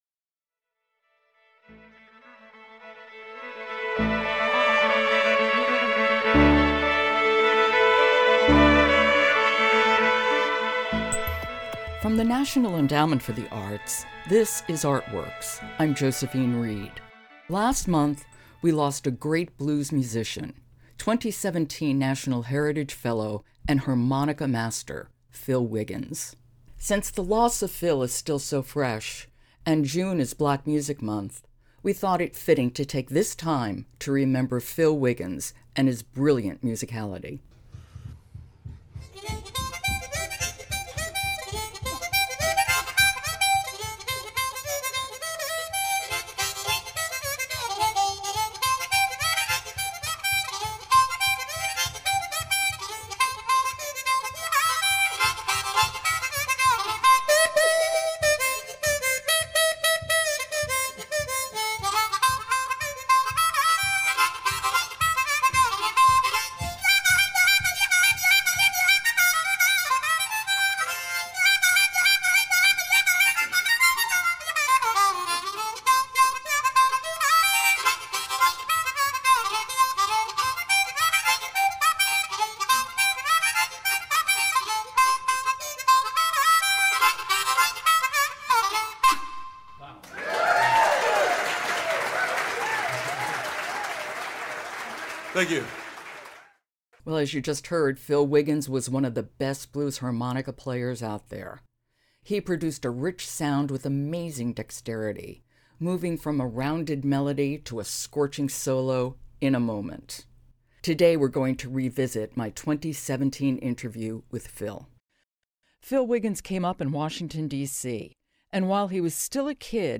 He produced a rich sound with amazing dexterity—moving from a rounded melody to a scorching solo in a moment. Today, we’re revisiting my 2017 interview with Phil.